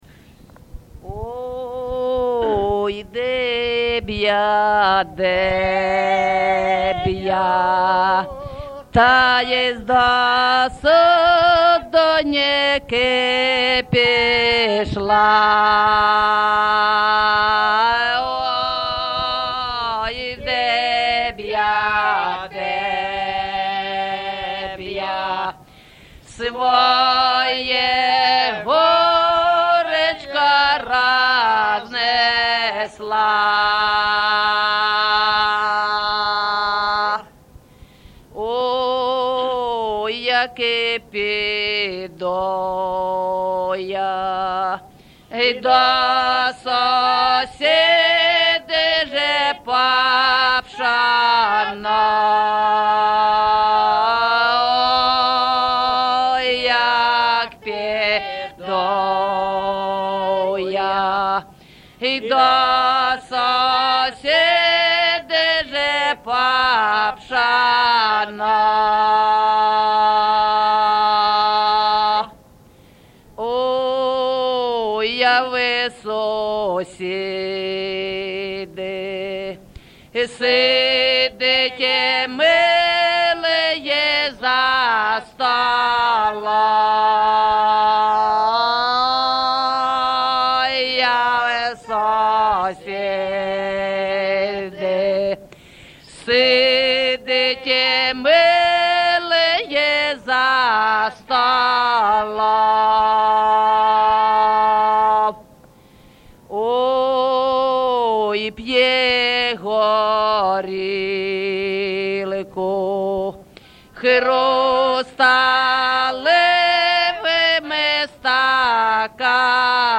ЖанрПісні з особистого та родинного життя
Місце записус-ще Калинівка, Бахмутський район, Донецька обл., Україна, Слобожанщина